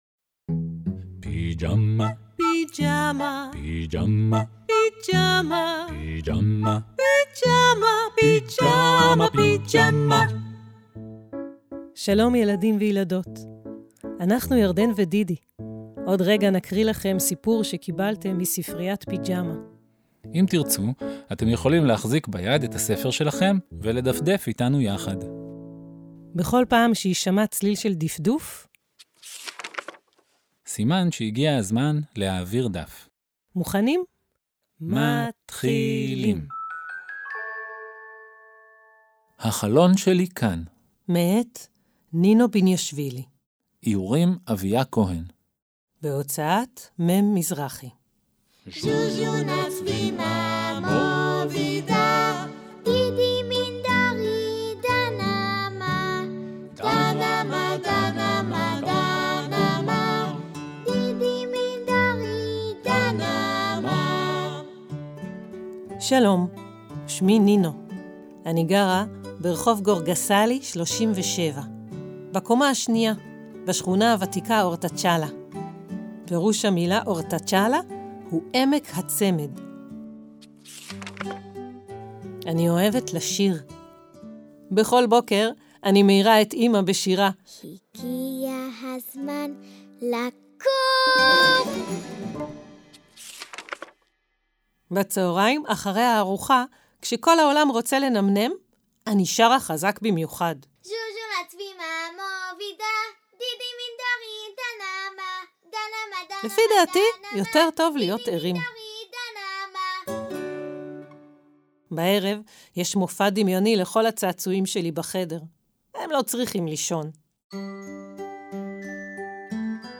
האזינו לסיפור "עצים מצפים לגשם" (כיתות ב') – ספריית פיג'מה קוראת לכם! – Lyssna här